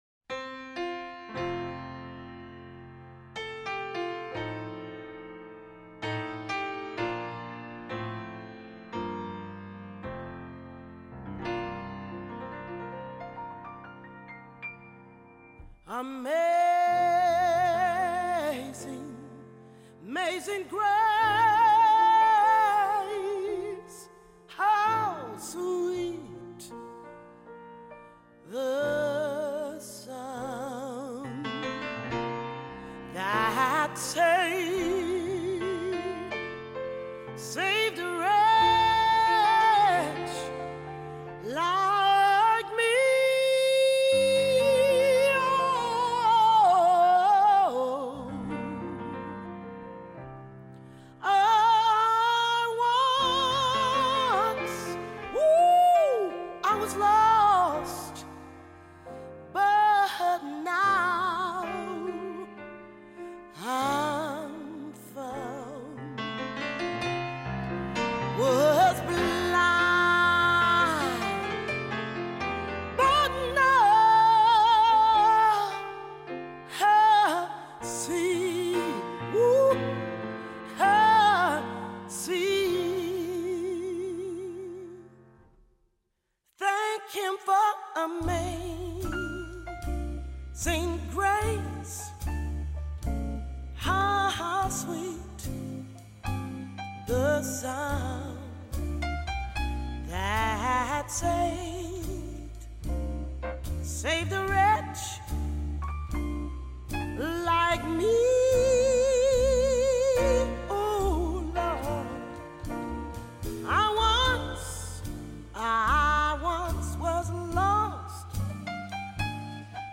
sax 1st 3rd 2nd 4th 5th
trp 1st 2nd 3rd 4th
trb 1st 2nd 3rd 4th